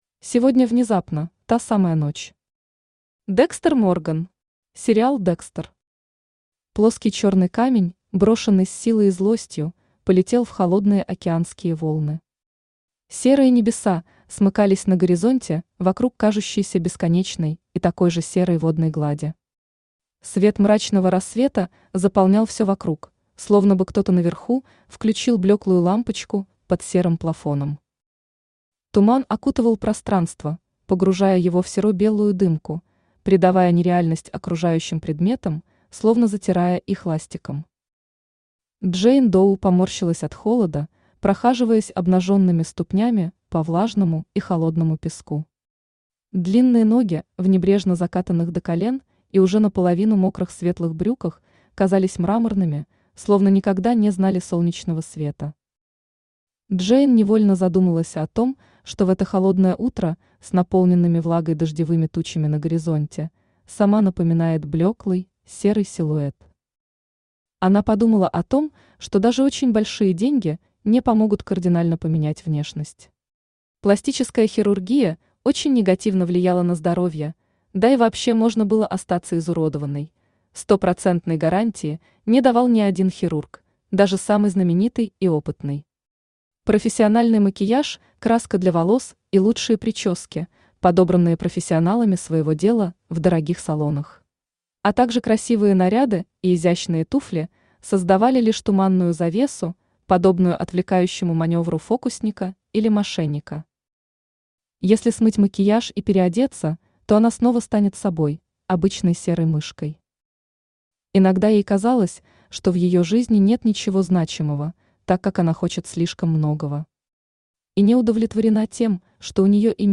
Аудиокнига Похищение | Библиотека аудиокниг
Aудиокнига Похищение Автор Кристина Воронова Читает аудиокнигу Авточтец ЛитРес.